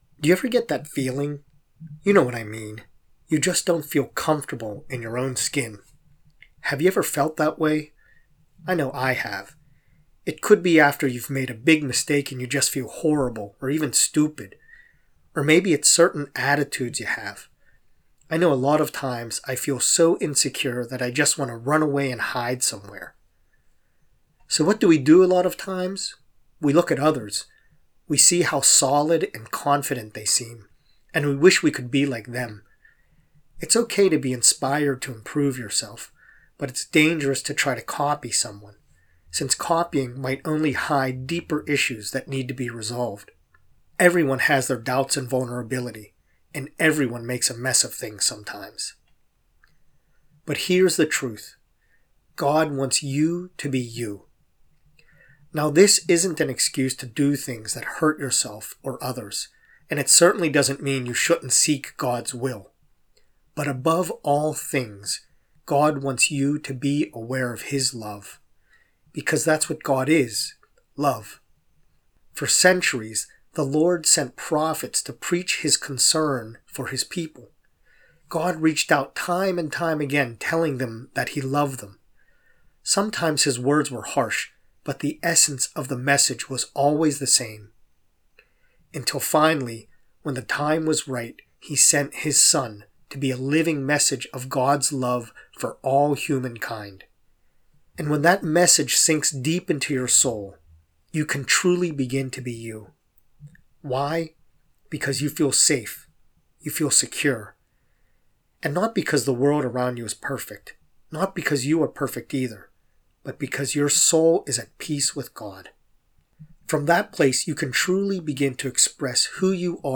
praryer-for-me-to-be-myself.mp3